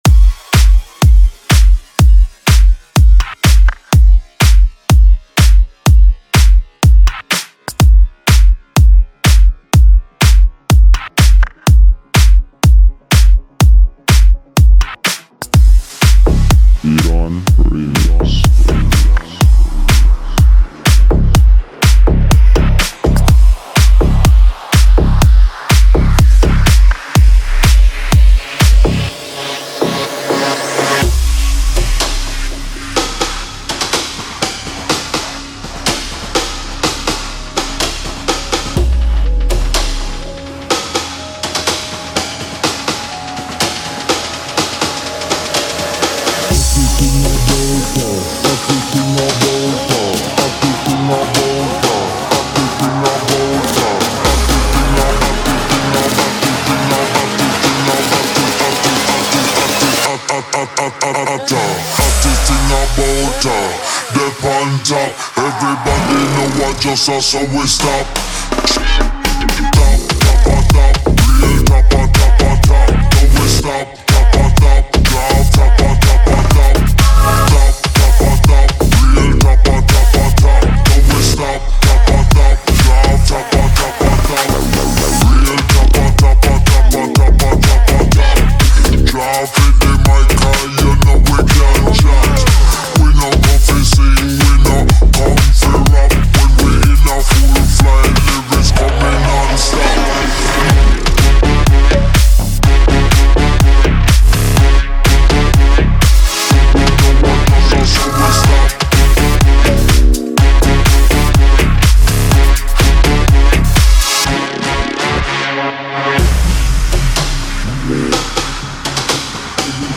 ریمیکس
Download new music for shuffle dance